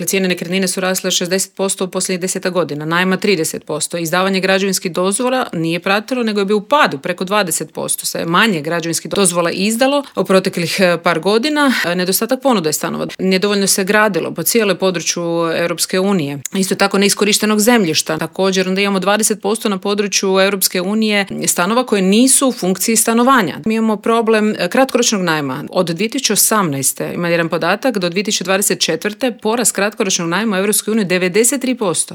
Upravo o toj temi razgovarali smo u Intervjuu Media servisa s HDZ-ovom europarlamentarkom Nikolinom Brnjac.